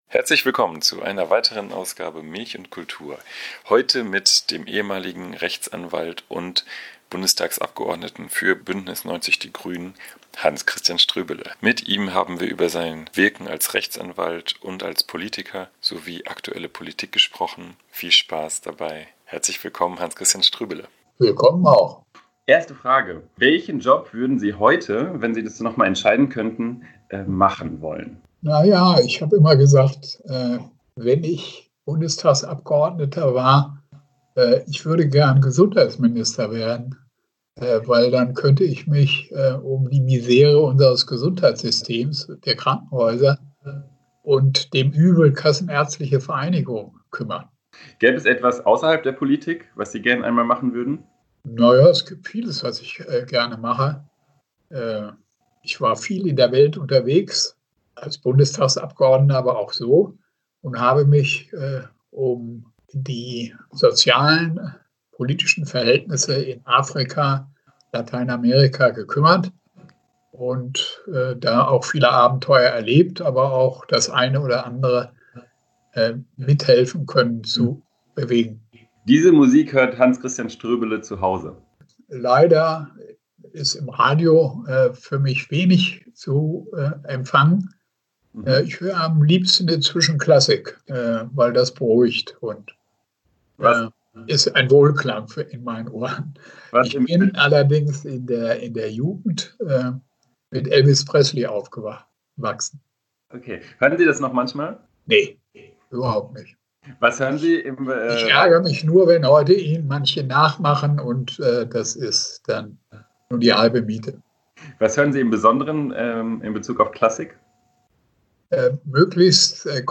Darüber und über vieles mehr haben wir mit unserem Gast am Telefon gesprochen. Wir bitten die (manchmal etwas ungenügende) Tonqualität zu entschuldigen.